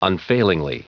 Prononciation du mot unfailingly en anglais (fichier audio)
Prononciation du mot : unfailingly